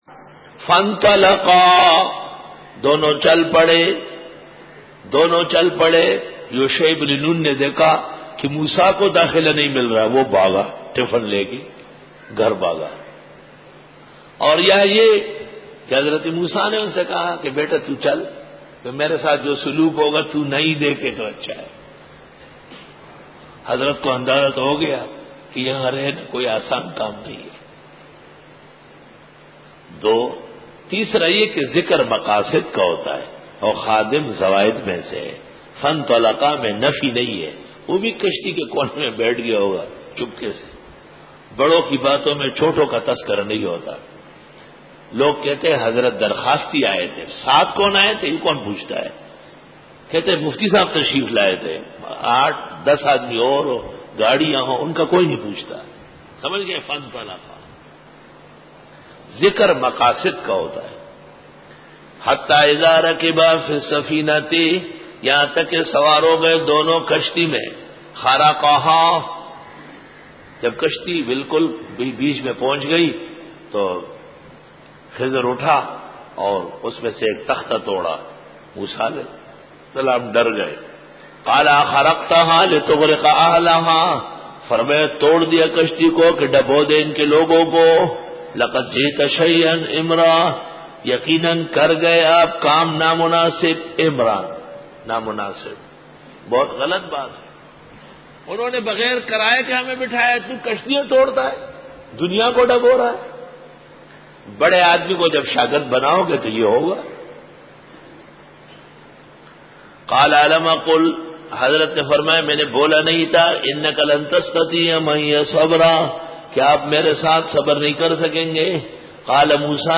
سورۃ الکھف رکوع-10 Bayan